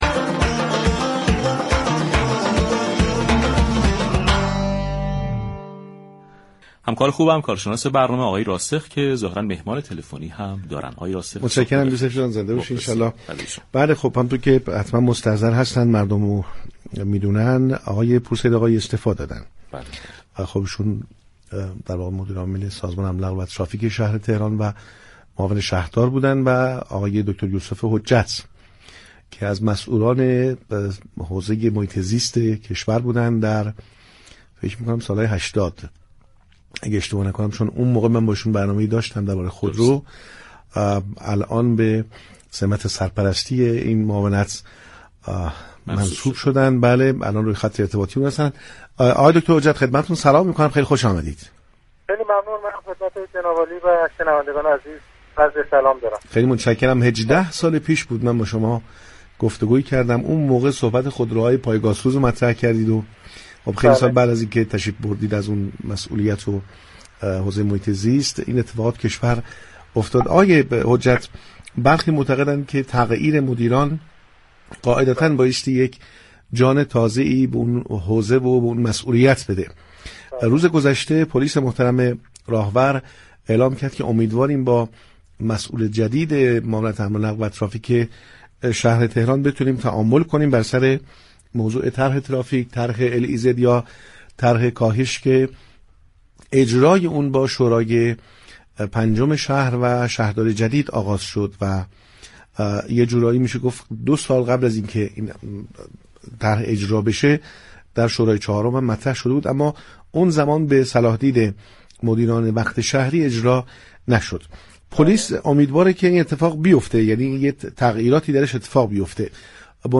یوسف حجت، سرپرست جدید سازمان حمل‌و‌نقل‌ و ترافیك شهرداری تهران در نخستین مصاحبه رسانه‌ای، درباره برنامه‌های جدید این سازمان با پارك شهر گفتگو كرد.